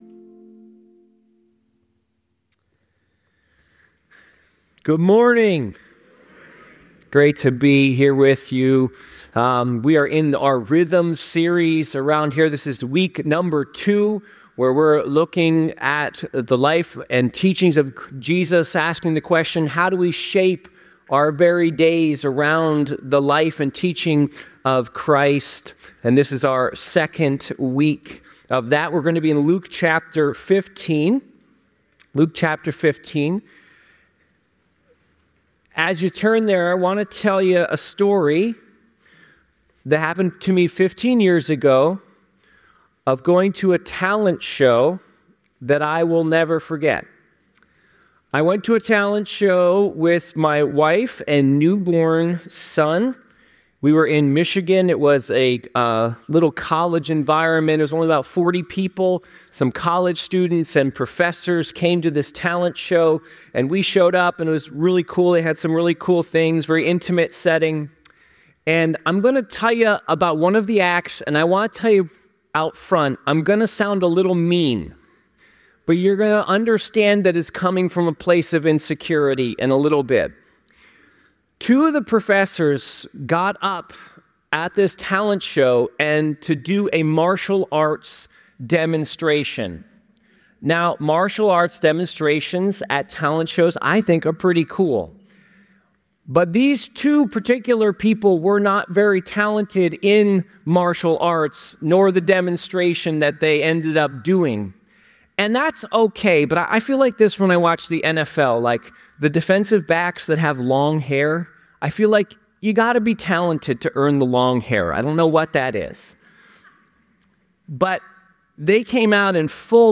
The sermon concludes with practical steps to cultivate trust and intimacy with God, encouraging believers to adopt spiritual disciplines like the Ignatian Examen to recognize God’s presence in their daily lives.